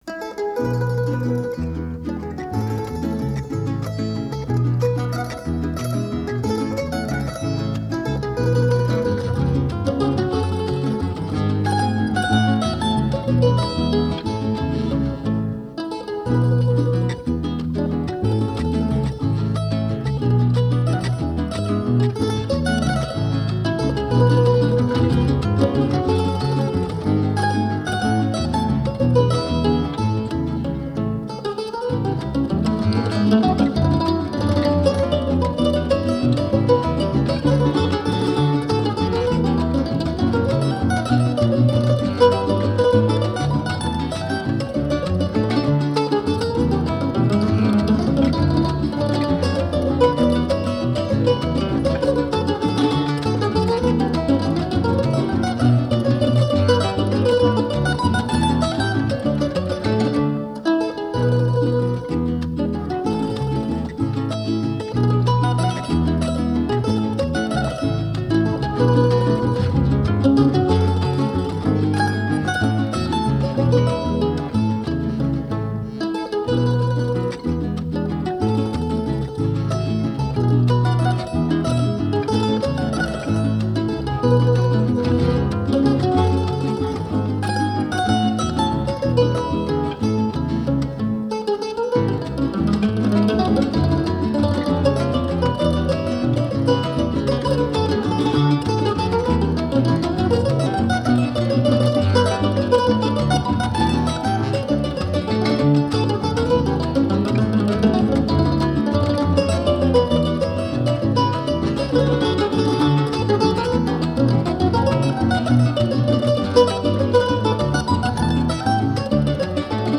Pars Today- La música de América Latina.